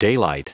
Prononciation du mot daylight en anglais (fichier audio)
Prononciation du mot : daylight